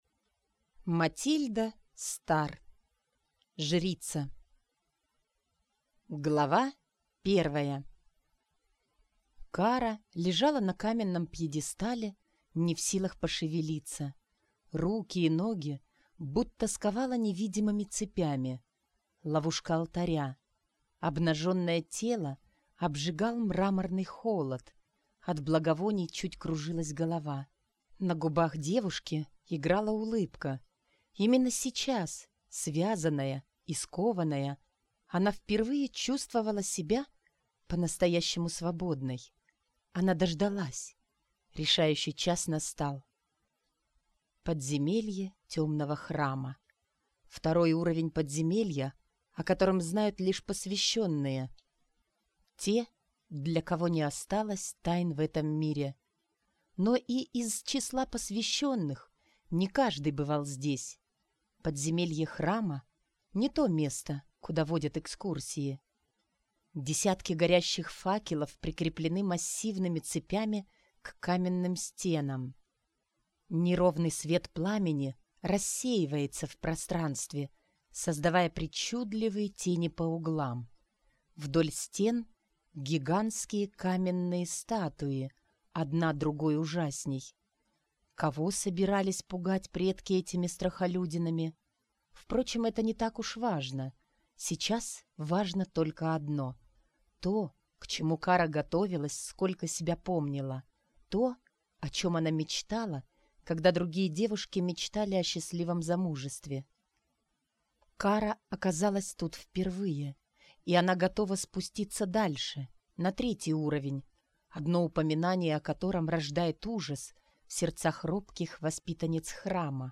Аудиокнига Жрица - купить, скачать и слушать онлайн | КнигоПоиск